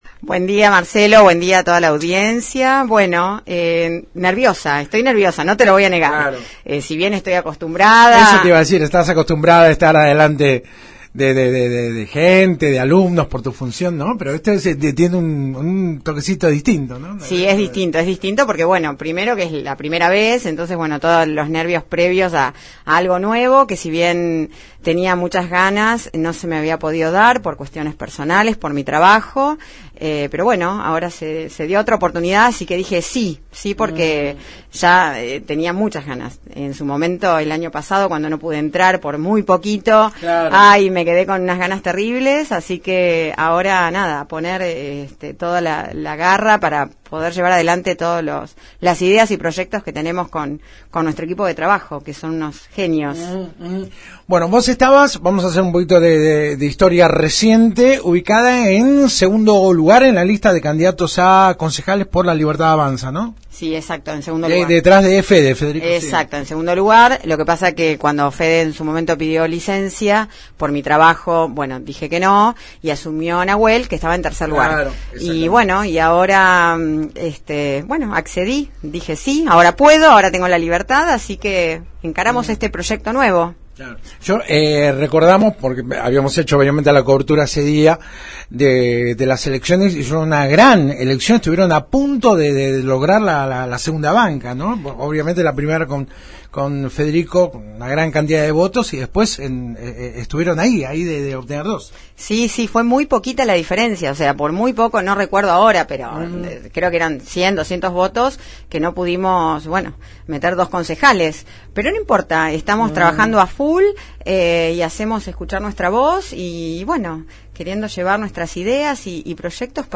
En charla con la 91.5 Cocola expresó que «con algo de nervios pero con muchas ganas y entusiasmo de llegar al Concejo Deliberante.